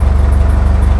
RbtTrkEngineA.wav